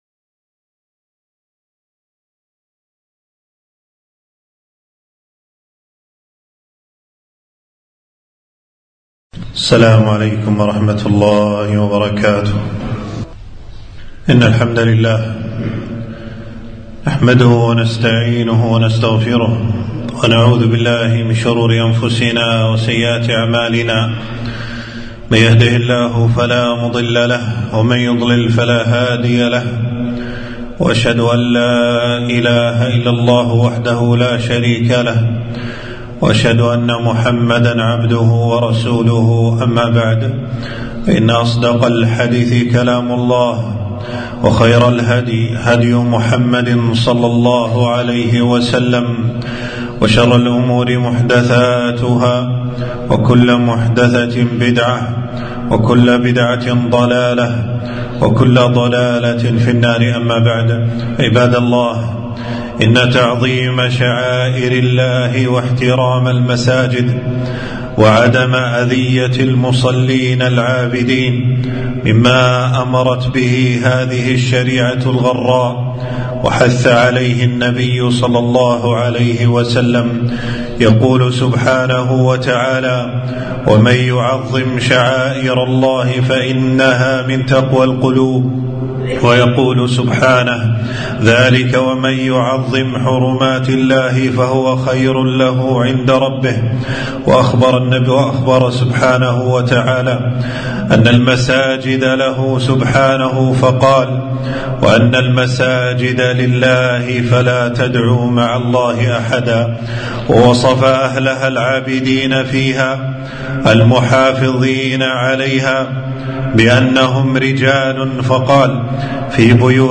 خطبة - النهي عن أذية المصلين في مساجد المسلمين